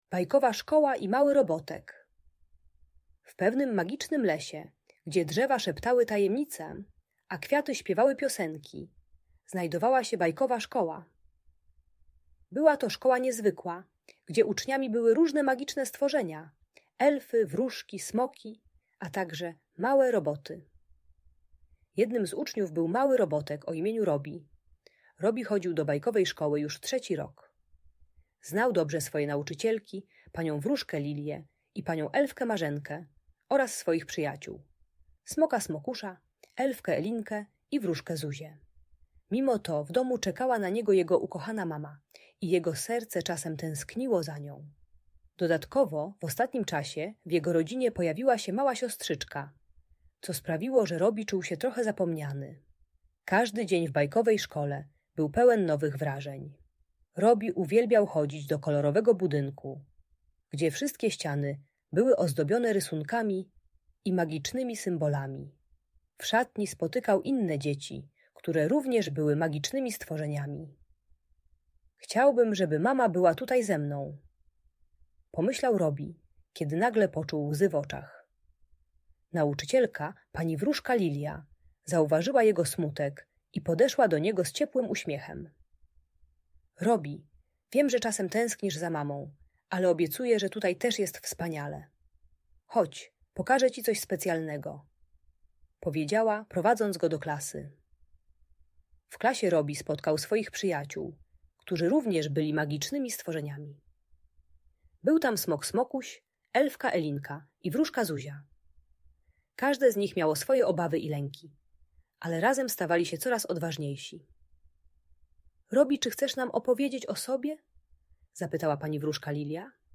Uczy techniki dzielenia się emocjami z przyjaciółmi i budowania pewności siebie przez wspólne zabawy. Audiobajka o przywiązaniu do matki.